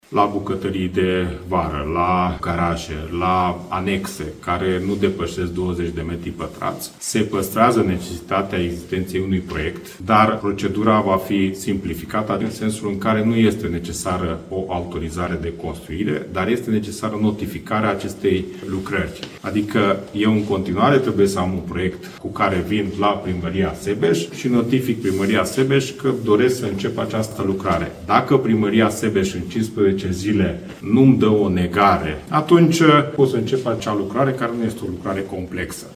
Ministrul Dezvoltării Cseke Attila speră că în acest fel va fi redusă birocrația și a explicat, într-un interviu acordat publicației Alba24, primăria va avea 15 zile la dispoziție pentru a decide dacă este nevoie sau nu de autorizație pentru acea construcție.
Ministrul Dezvoltării, Cseke Attila: „Nu este necesară o autorizare de construire, dar este necesară notificare a acestei lucrări”